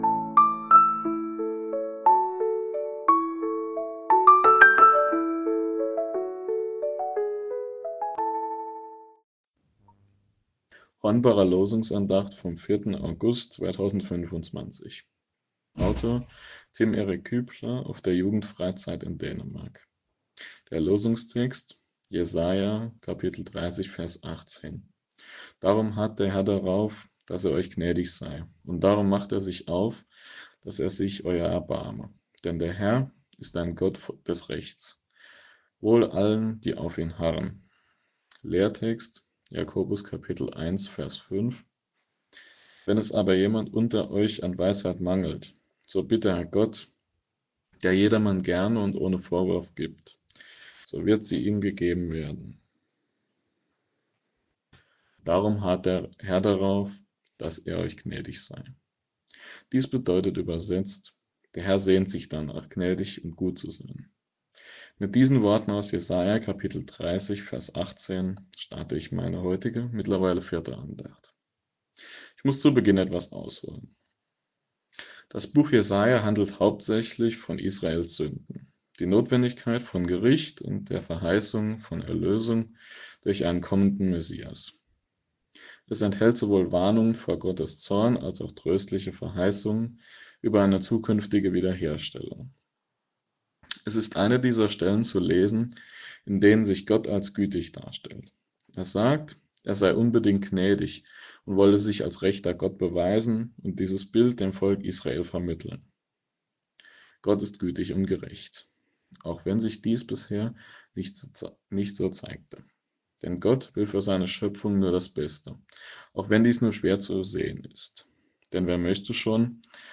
Losungsandacht für Montag, 04.08.2025